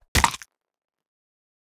KS_Gore Punch_1.wav